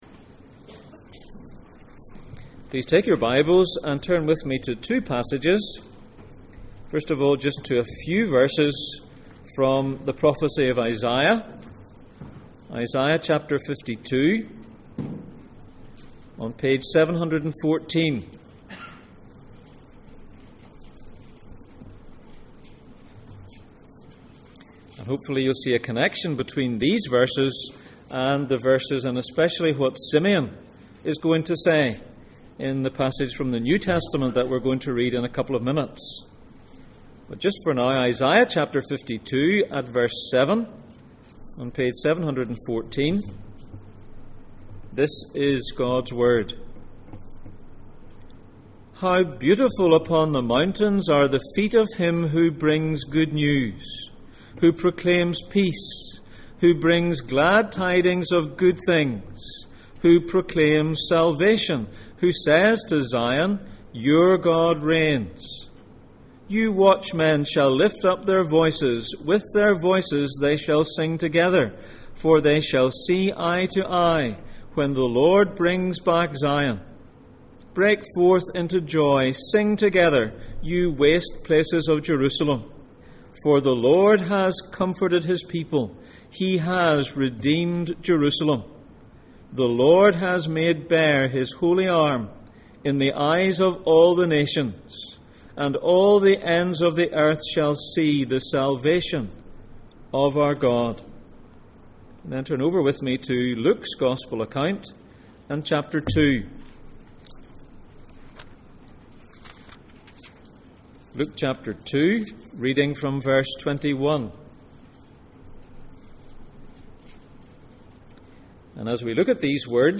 Passage: Luke 2:21-38, Isaiah 52:7-10 Service Type: Sunday Morning